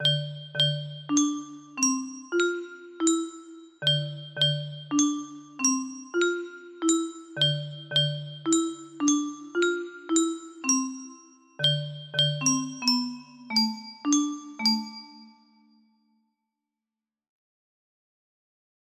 Happy birthday music box melody